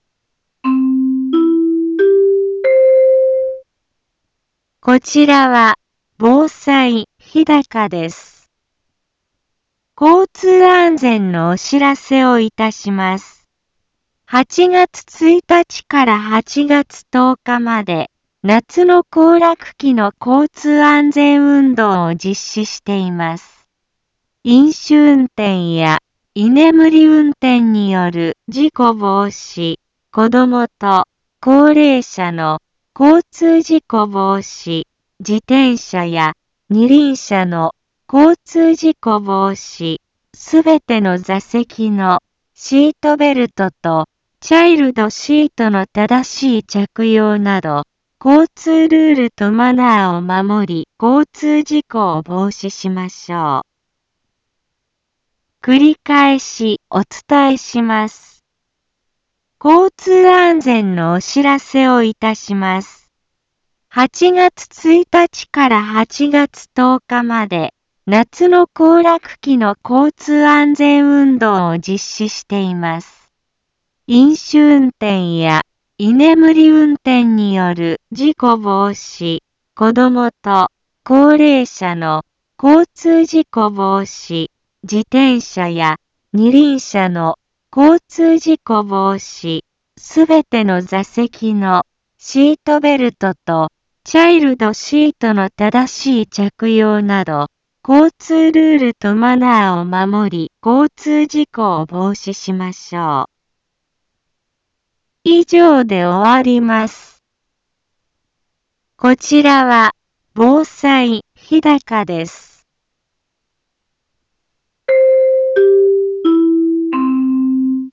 一般放送情報